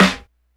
snare02.wav